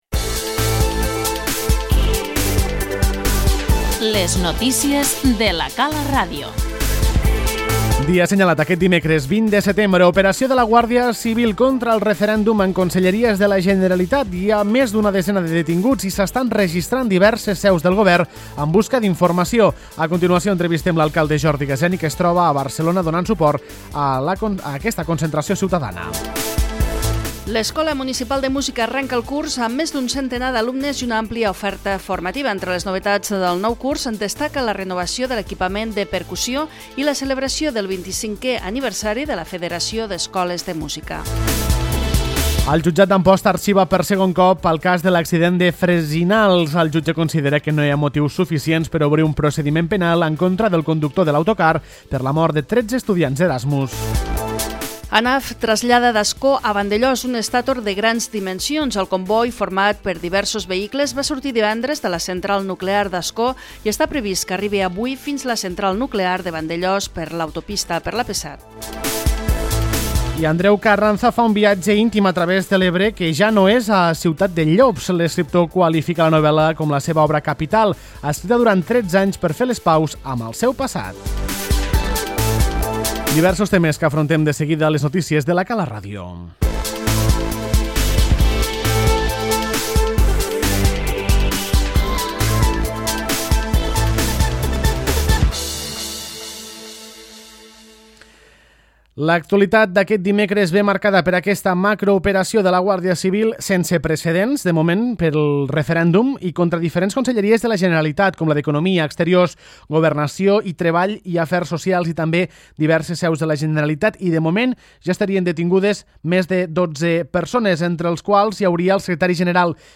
Entrevistem l'alcalde Jordi Gaseni que es troba a Barcelona donant suport.